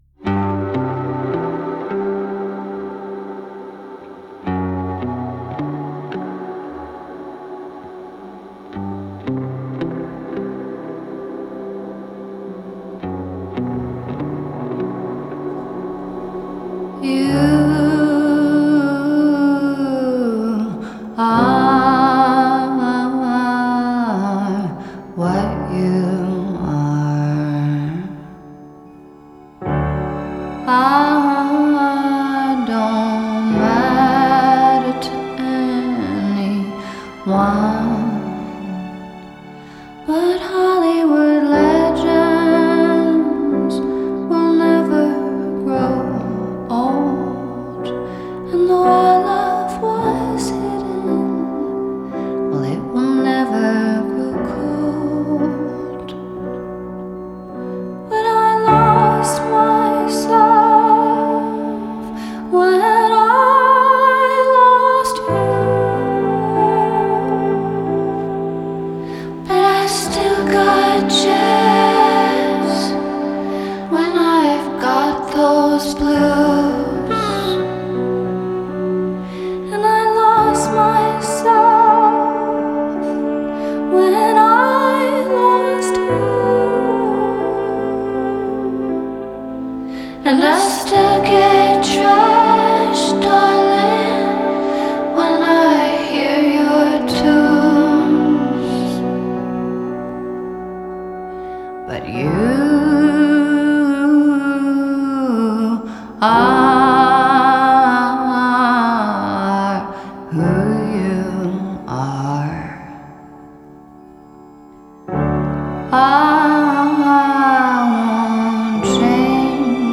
Genre: Pop, Singer-Songwriter